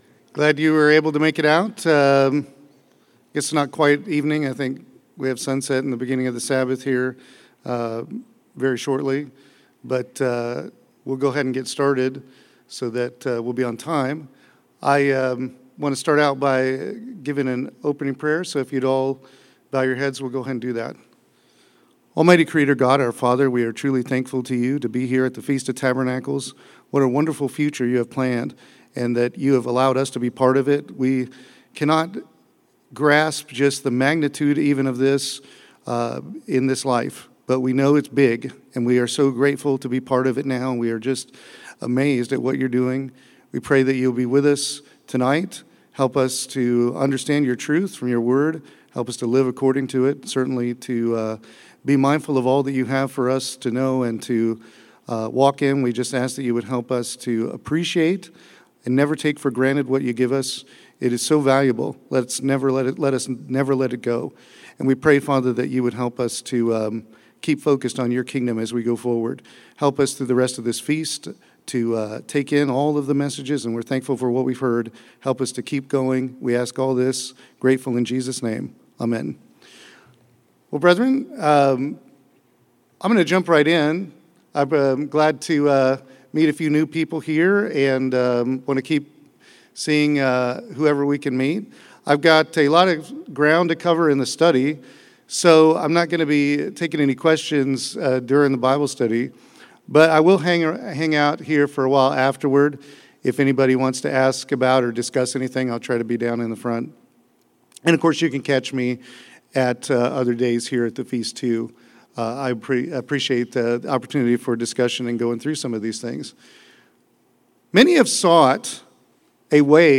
This sermon was given at the Branson, Missouri 2021 Feast site.